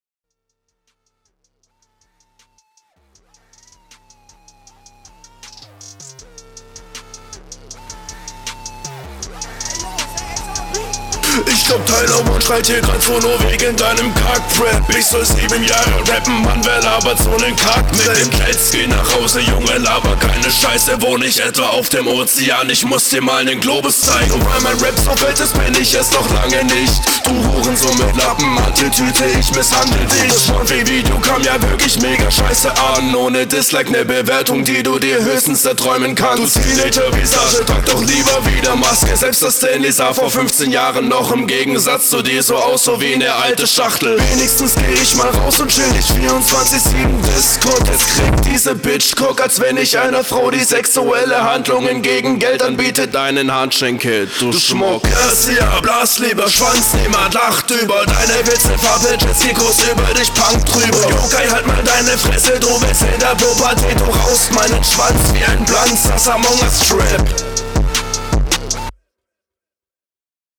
Battle Runden